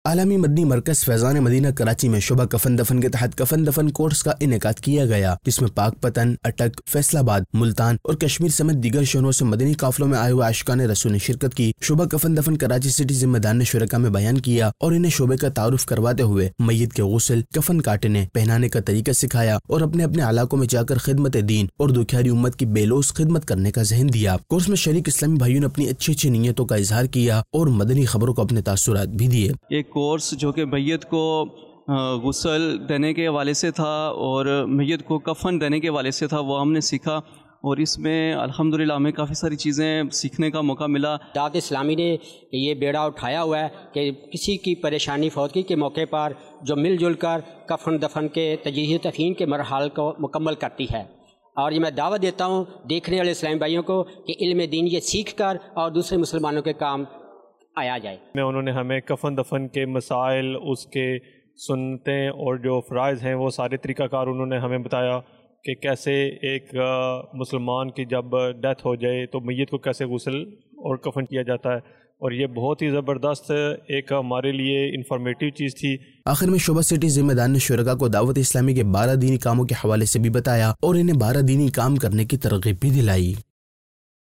News Clips Urdu - 02 February 2023 - Aalmi Madani Markaz Faizan e Madina Main Kafan Dafan Course Ka Ineqad Mukhtalif Shehron Say Aashiqan e Rasool Shareek Feb 8, 2023 MP3 MP4 MP3 Share نیوز کلپس اردو - 02 فروری 2023 - عالمی مدنی مرکز فیضان مدینہ میں کفن دفن کورس کا انعقاد مختلف شہروں سے عاشقان رسول شریک